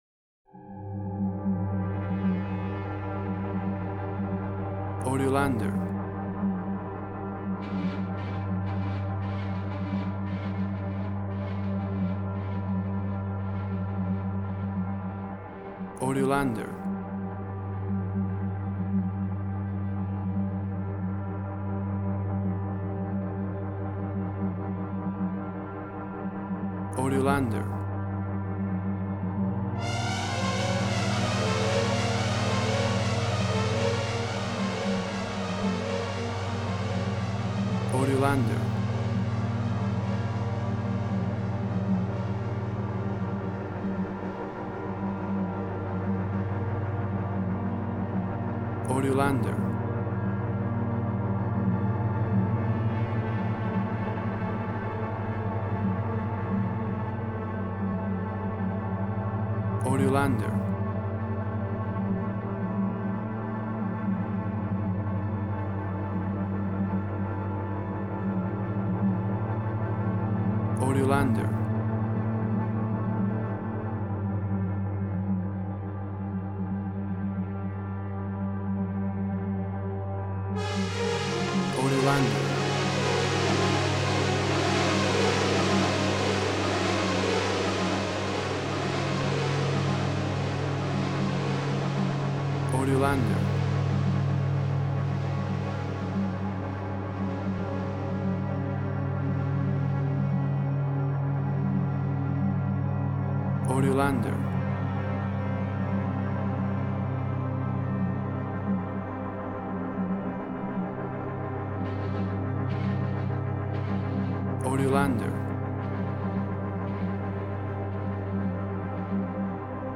Sounds of strange atmospheres and tension, mystery suspense
WAV Sample Rate: 16-Bit stereo, 44.1 kHz